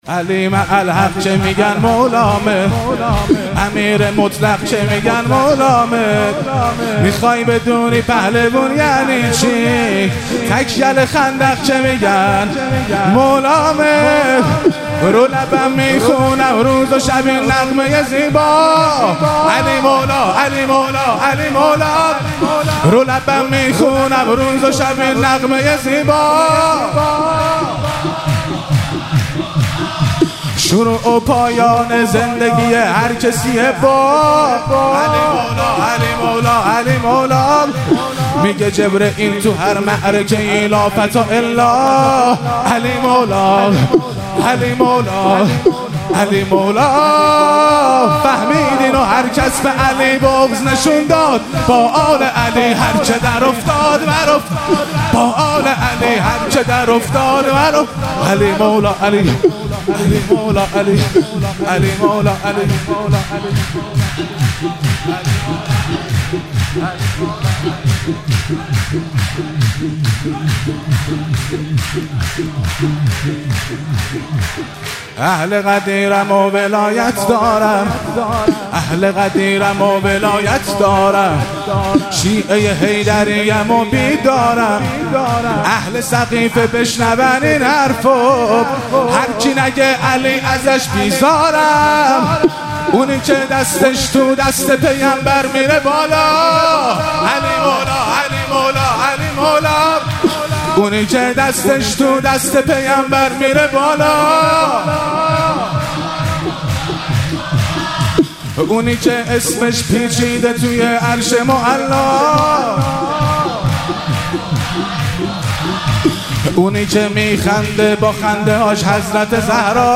مراسم جشن شب دوم ویژه برنامه عید سعید غدیر خم 1444
شور- علی مع الحق که میگن مولامه